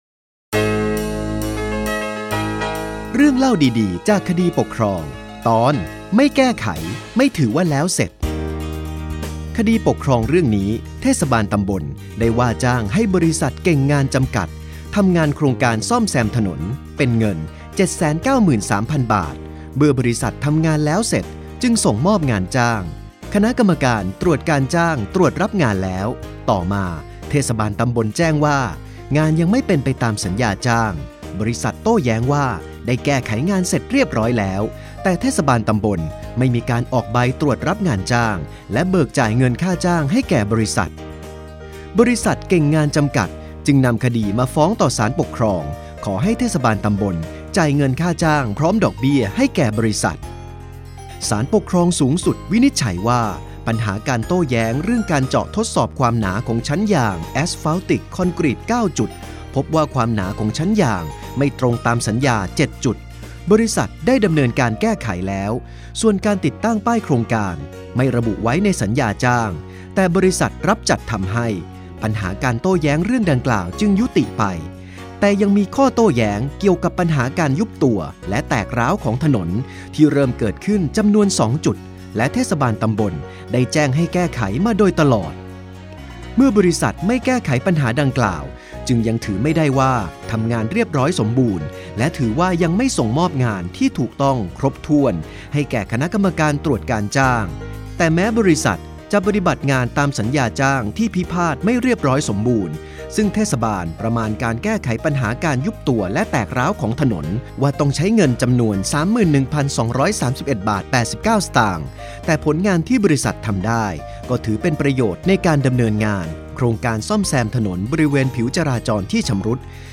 สารคดีเสียง เรื่องเล่าดีดีจากคดีปกครอง 4 ตอนที่ 1 - 5